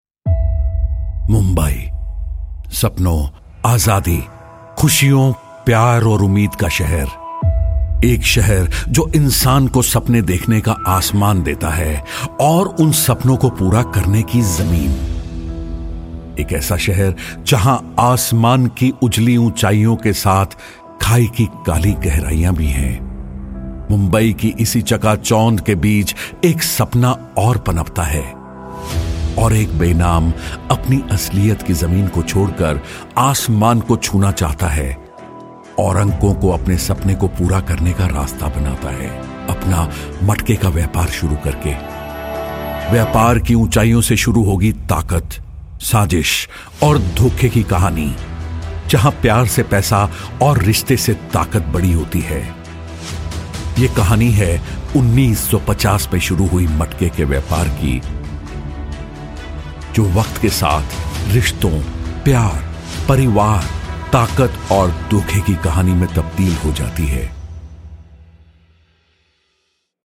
Voice Over Artist in Delhi NCR - Male Hindi & English dubbing artist in India
I am a Professional Indian Hindi and English Voice Over Artist and Male Dubbing Artist in Delhi NCR, Mumbai, Bangalore India.